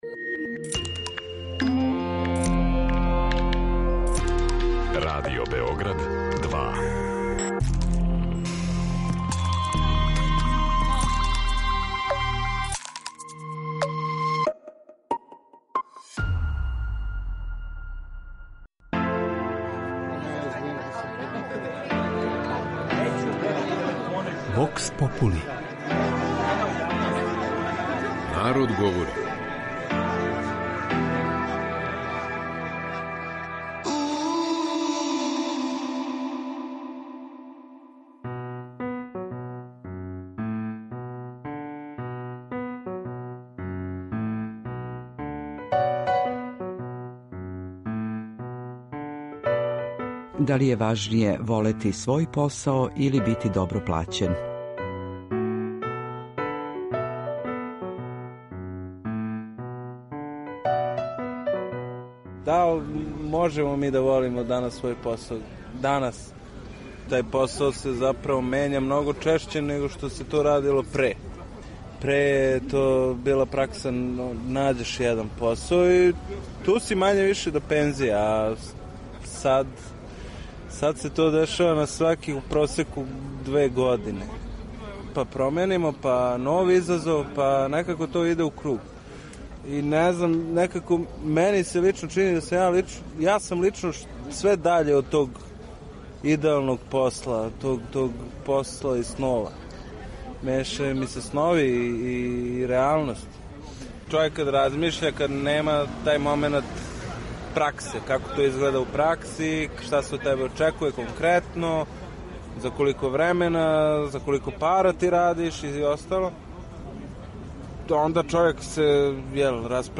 У данашњој емисији, питали смо наше суграђане: „Да ли је важније волети свој посао или добро зарађивати?"
Вокс попули